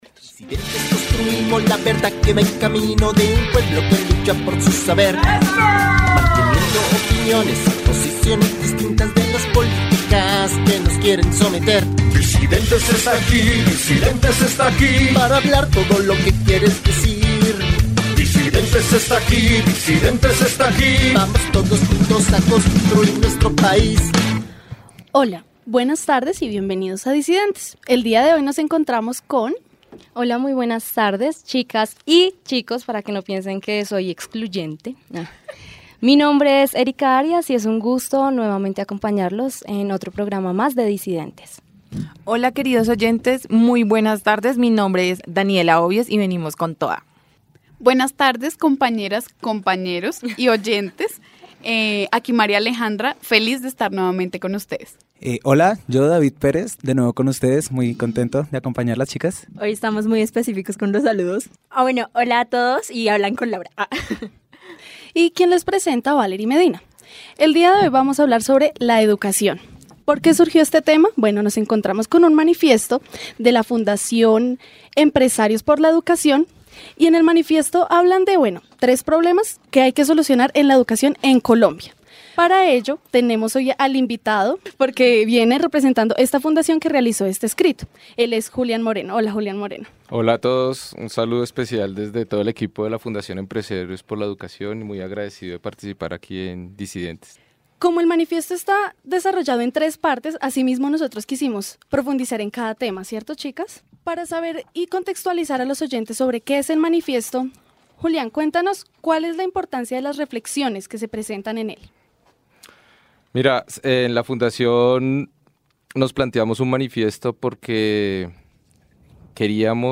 Y como es tradición en Disidentes, contamos con excelentes entrevistas, opiniones y buena música.